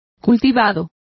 Complete with pronunciation of the translation of cultivated.